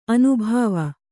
♪ anubhāva